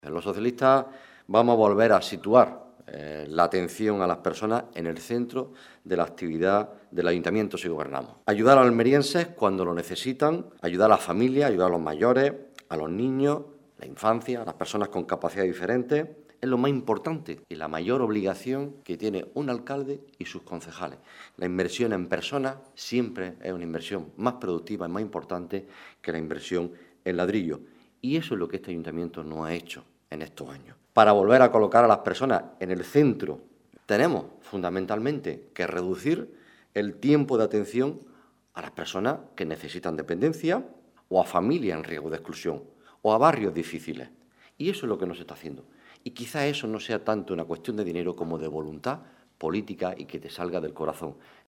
Rueda de prensa ofrecida por el candidato del PSOE a la Alcaldía de Almería, Juan Carlos Pérez Navas